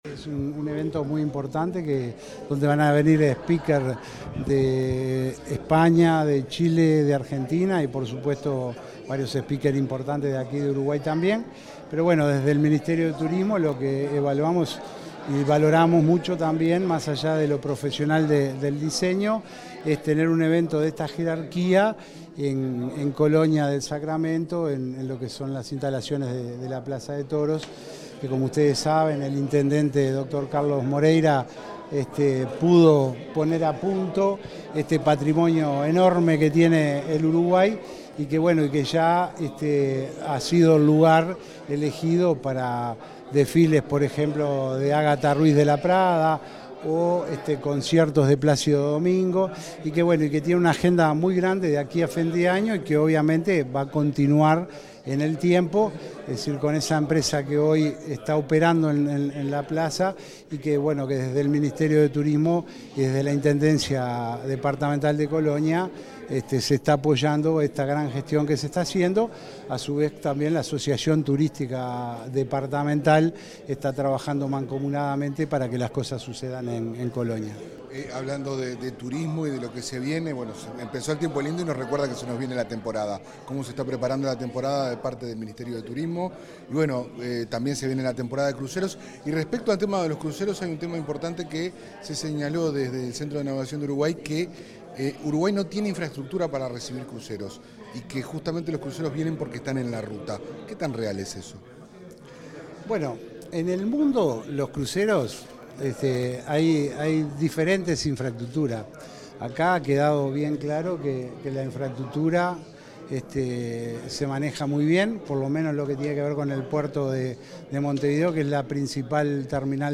Declaraciones del director nacional de Turismo, Roque Baudean
El director nacional de Turismo, Roque Baudean, dialogó con la prensa, luego de participar en el lanzamiento de la primera edición de Design Week
El evento se realizó este martes 17 en la sede del Ministerio de Turismo.